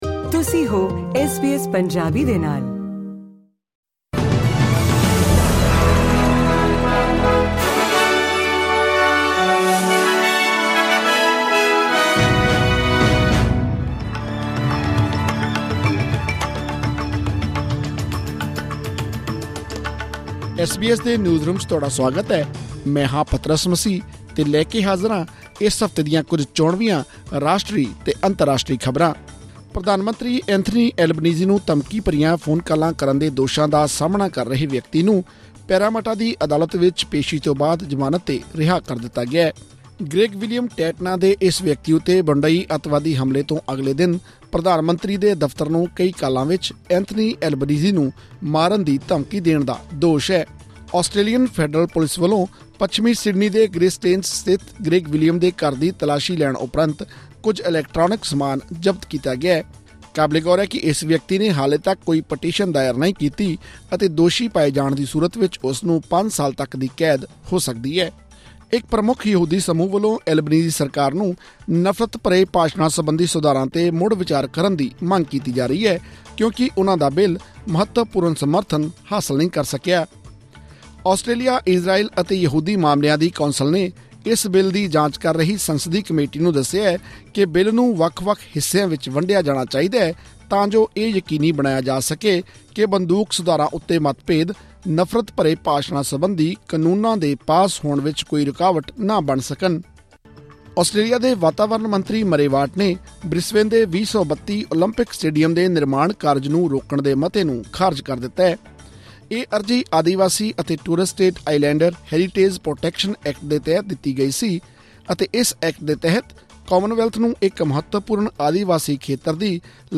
Top news updates of the week in Punjabi.